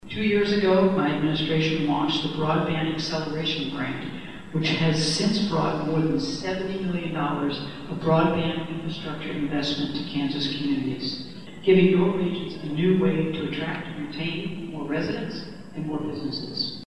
Governor Kelly addresses the 2022 Kansas Ag Summit in Manhattan